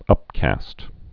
(ŭpkăst)